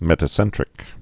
(mĕtə-sĕntrĭk)